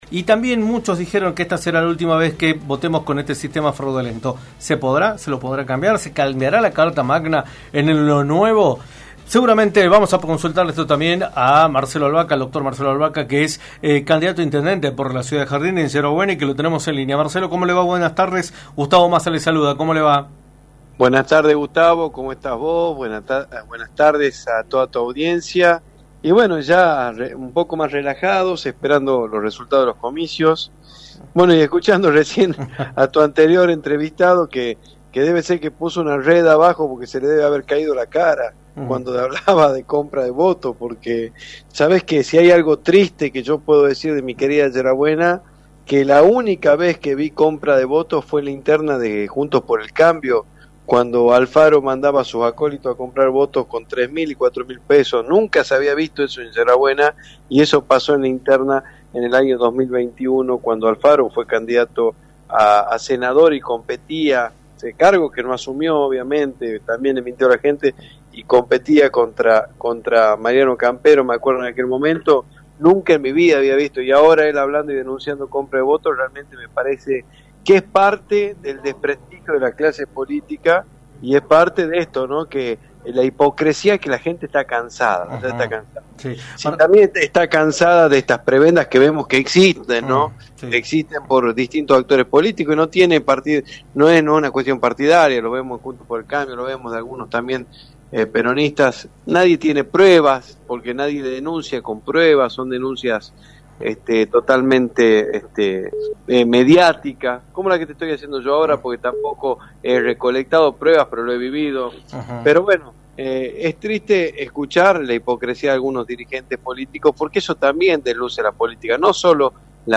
en entrevista por para Radio del Plata, por la 93.9.